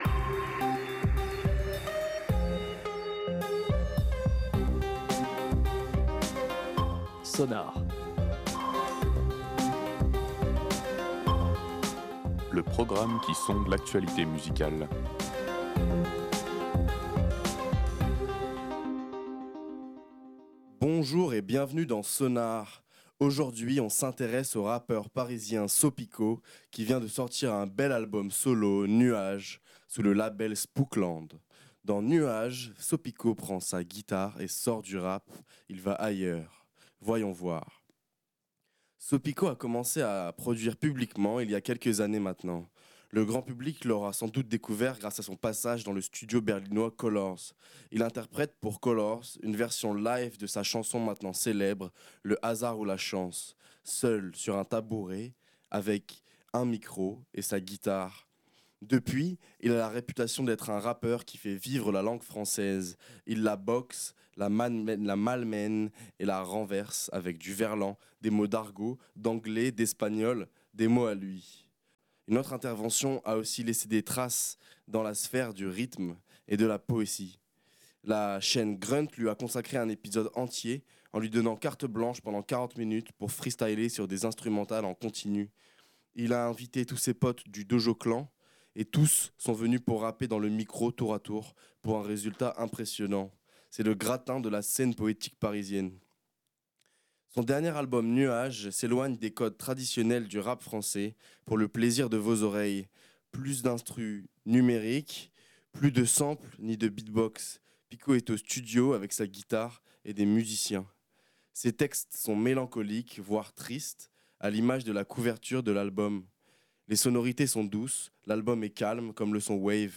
Réécoutez l'émission
Plus acoustique, plus mélancolique.